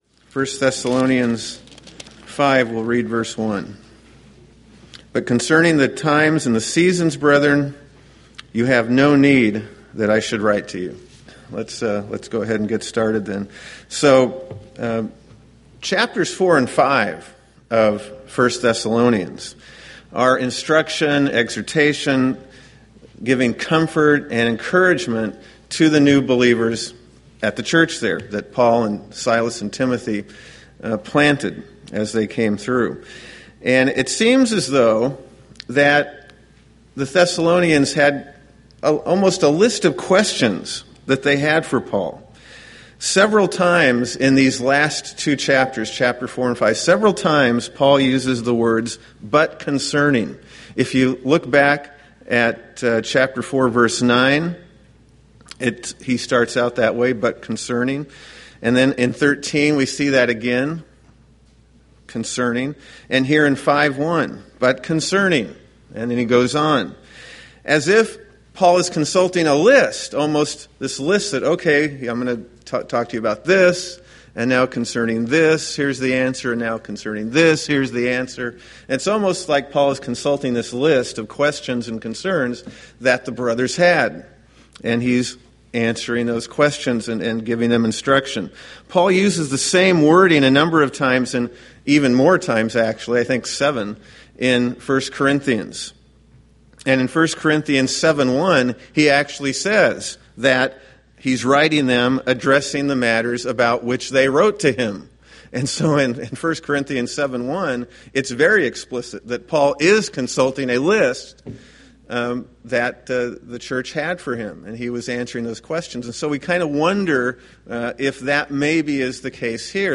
1 Thessalonians Sermon Series